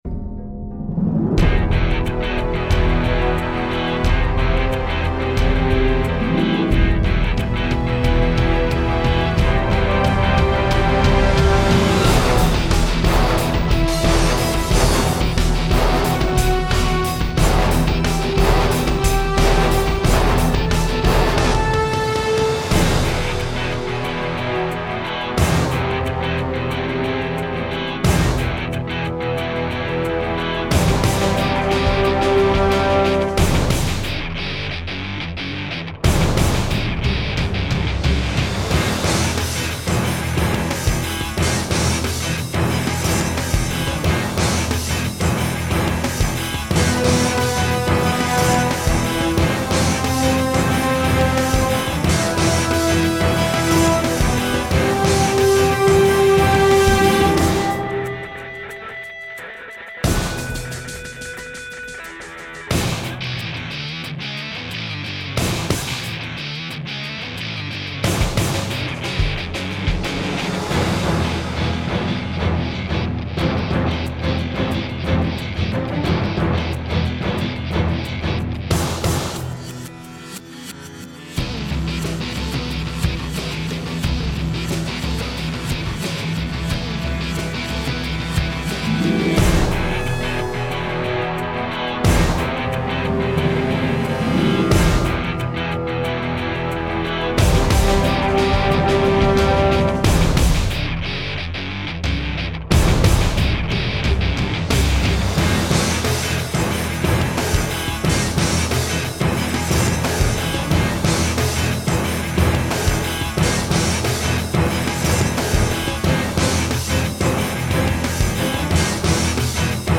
Главная музыкальная тема